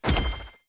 box_pickup.wav